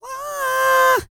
E-CROON 3028.wav